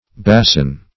bason - definition of bason - synonyms, pronunciation, spelling from Free Dictionary Search Result for " bason" : The Collaborative International Dictionary of English v.0.48: Bason \Ba"son\ (b[=a]"s'n), n. A basin.